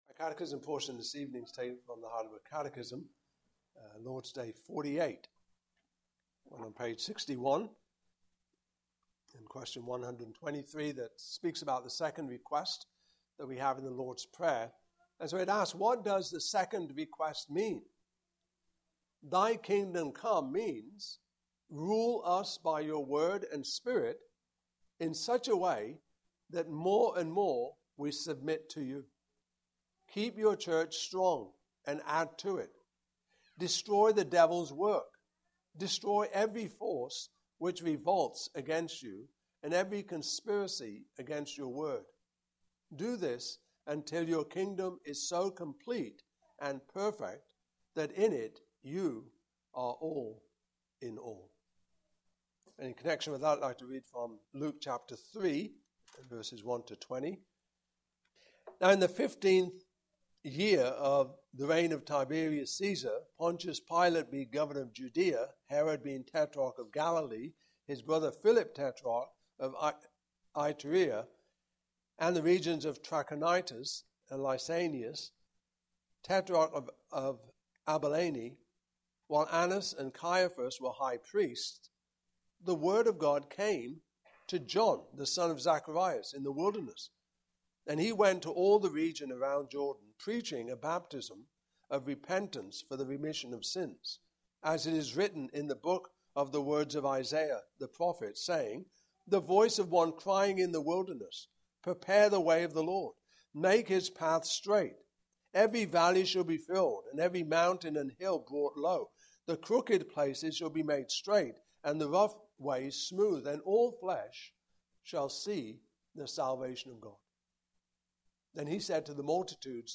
Passage: Luke 3:1-20 Service Type: Evening Service